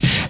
player_collision.wav